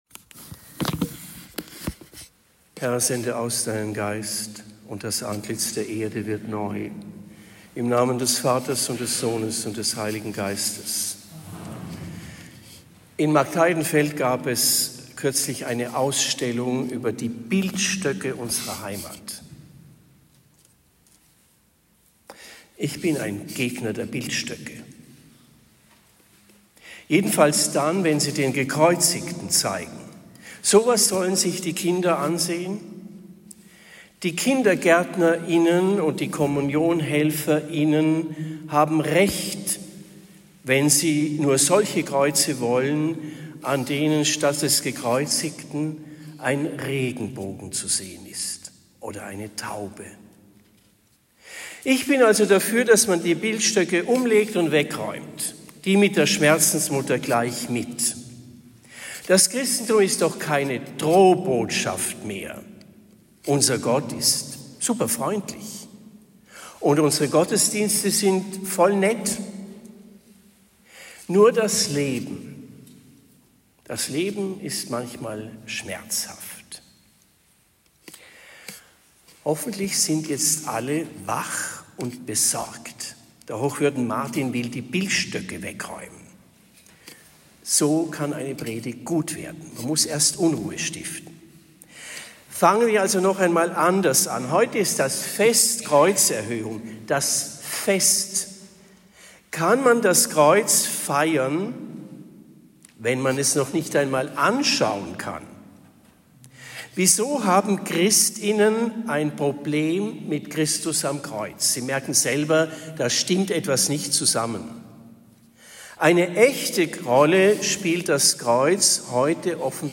Fest der Kreuzerhöhung Predigt in Windheim am 14. September 2025